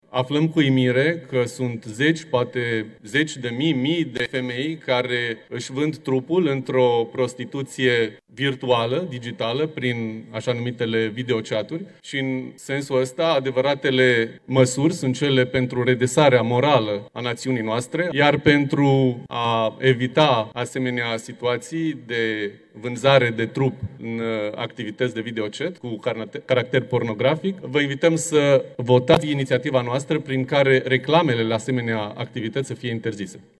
Deputatul Alin Coleșa: „Sunt zeci, poate zeci de mii, mii de femei într-o prostituție virtuală, digitală, prin așa-numitele videochat-uri”